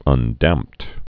(ŭndămpt)